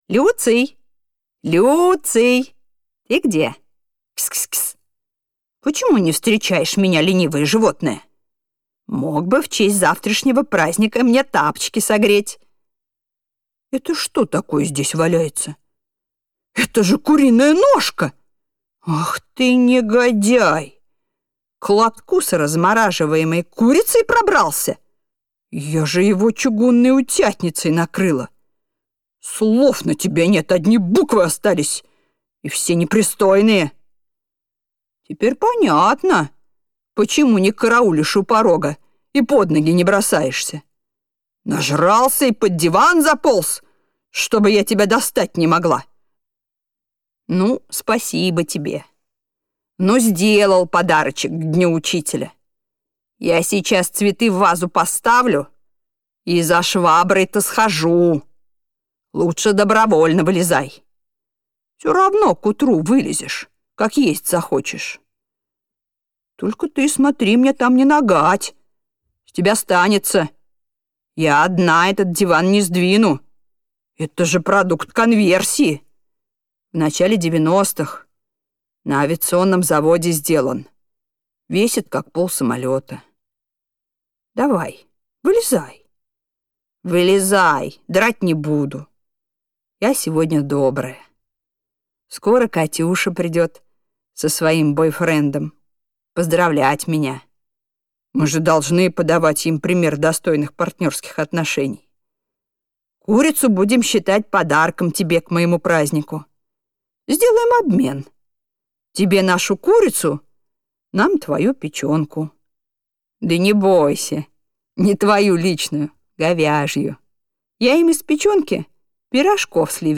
Аудиокнига Школа. Точка. Ру | Библиотека аудиокниг